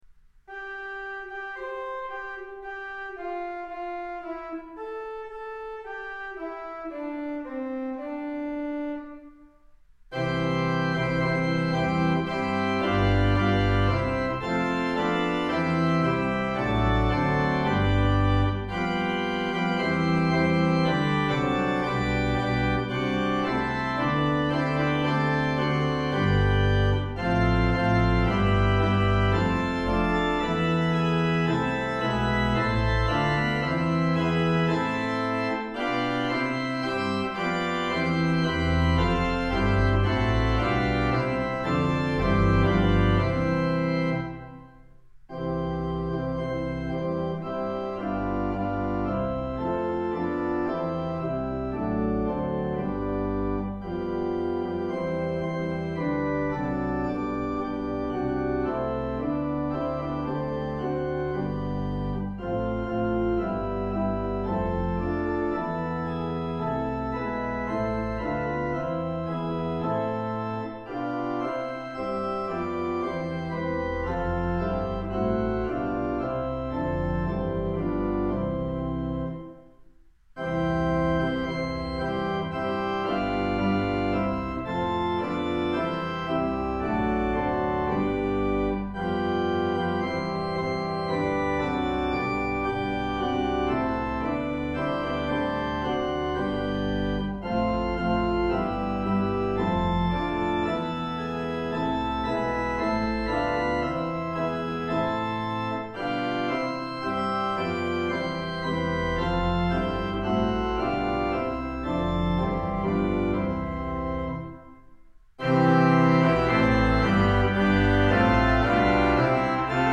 Organ: Moseley